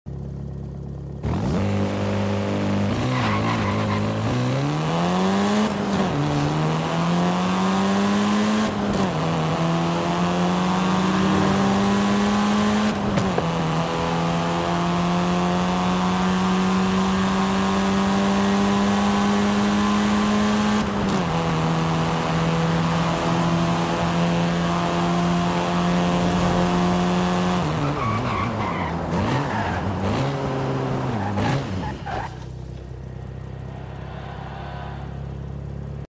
2008 Renault Megane R26.R Launch sound effects free download